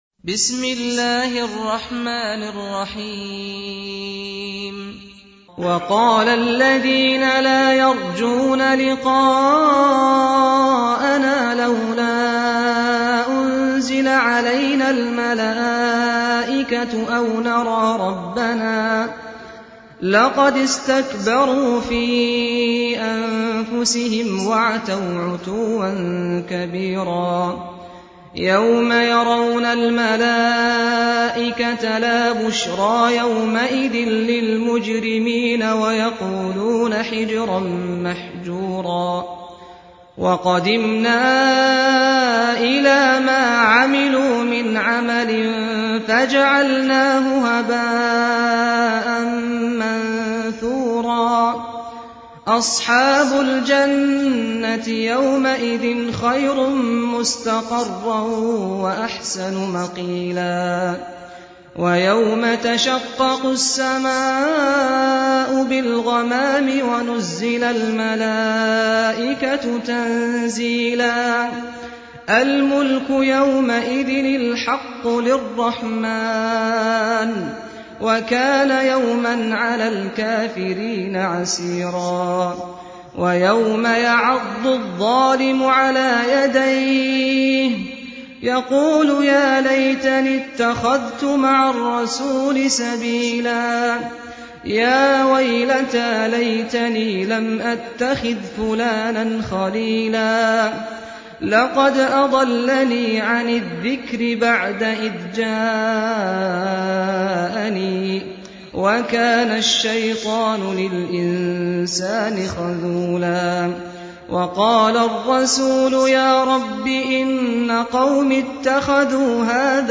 قرائت ترتیل «جزء 19» قرآن كریم با صدای استاد سعد الغامدی | به مدت 49 دقیقه
❖ دانلود ترتیل جزء نوزده قرآن کریم با صدای دلنشین استاد سعد الغامدی | مدت : 49 دقیقه